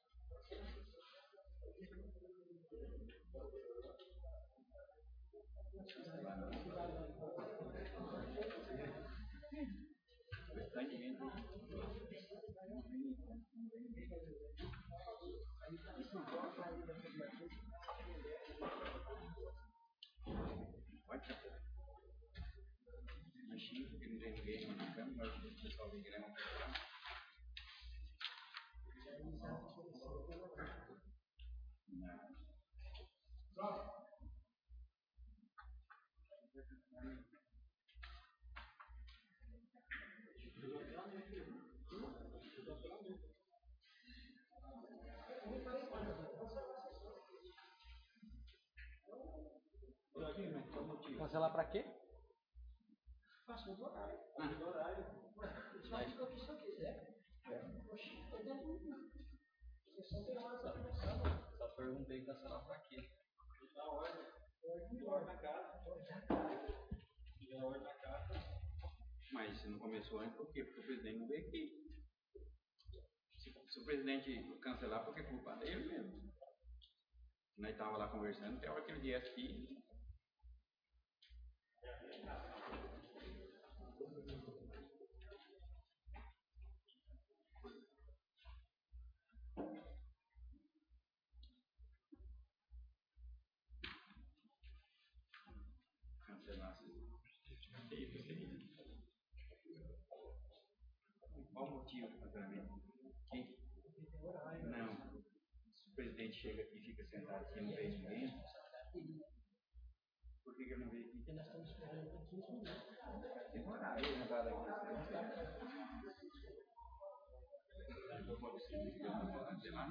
Áudio Sessão Ordinária